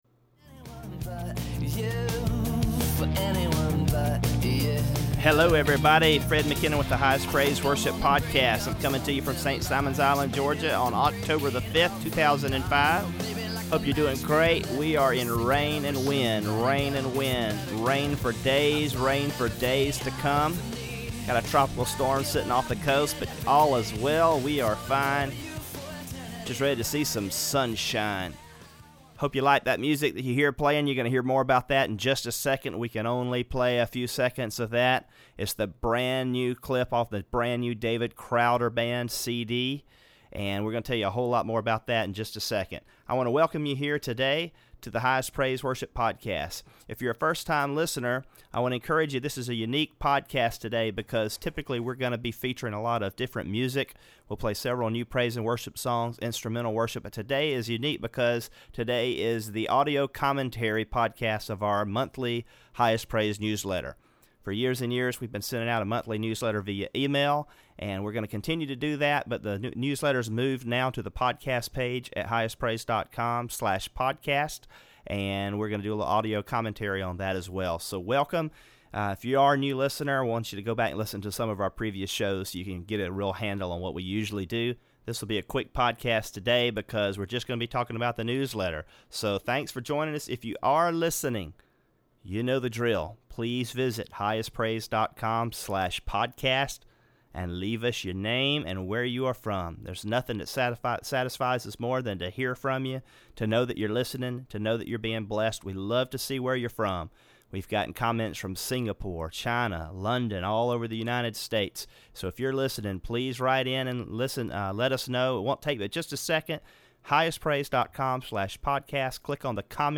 First – notice … you can “read” the newsletter here, but I have some audio commentary associated with it in the “podcast”.